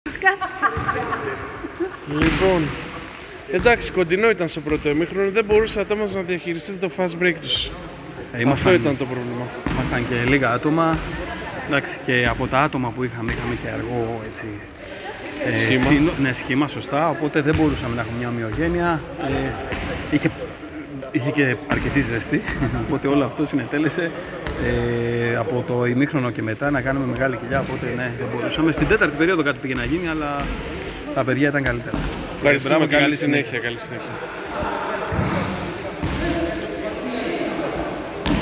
GAME INTERVIEWS:
Παίκτης ΕΛΛΑΚΤΩΡ